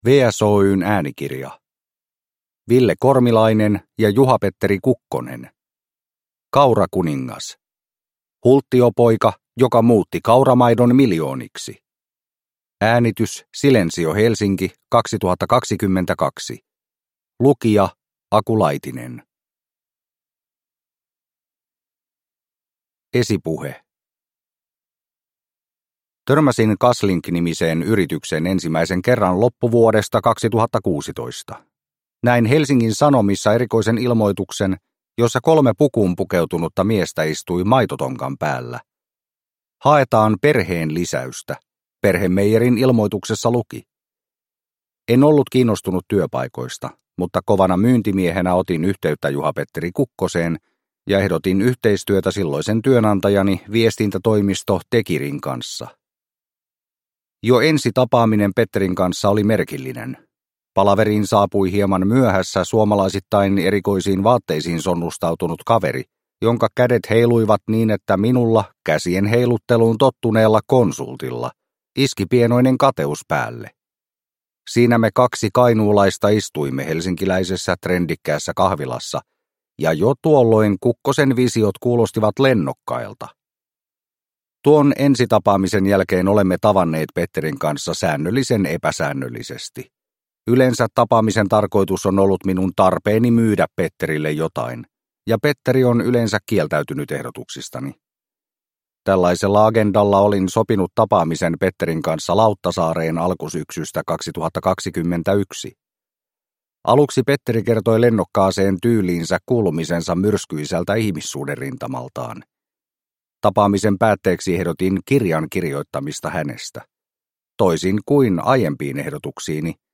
Kaurakuningas – Ljudbok – Laddas ner